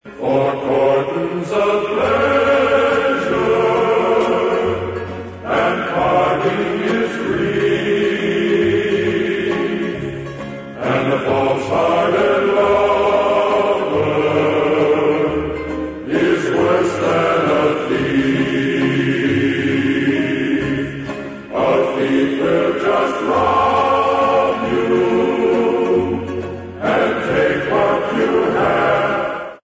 Ηχητική μπάντα παράστασης
sound track 03, διάρκεια 29'', τραγούδι